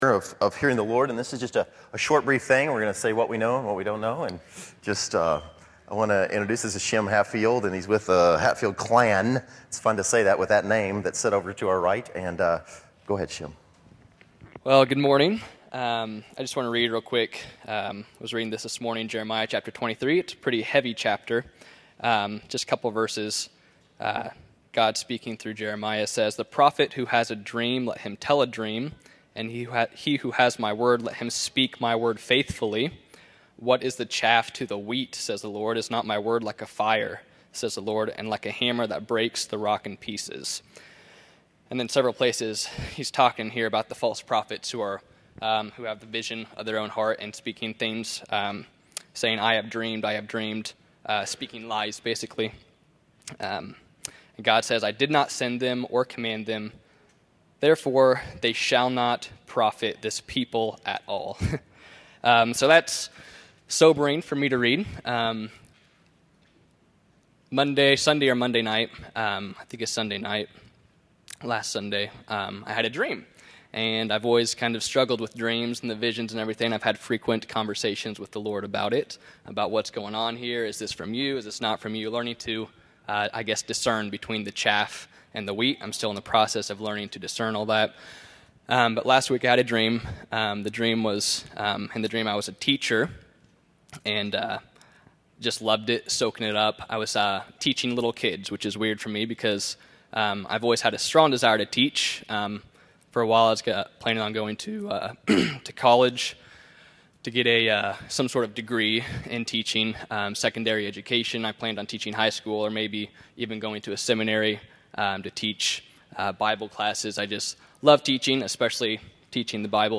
Category: Testimonies      |      Location: El Dorado